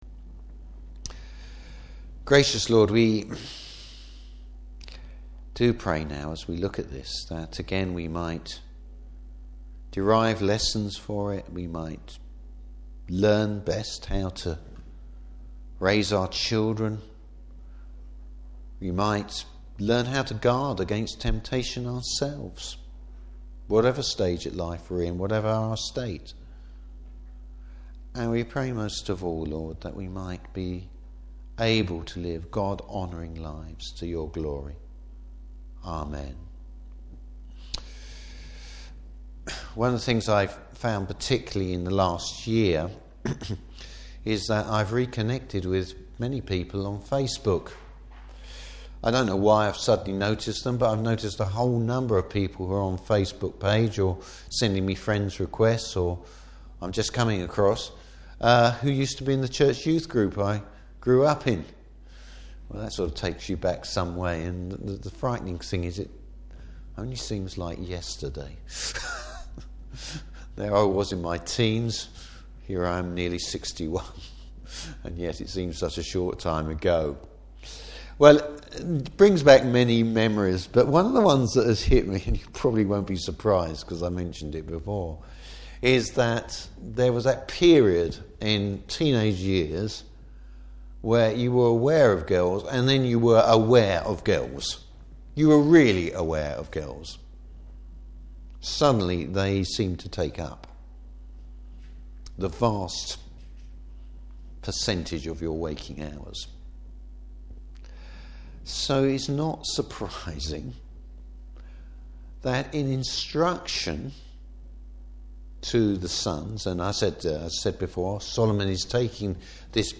Service Type: Morning Service Advice to avoid to avoid the difficulties of sexual temptation.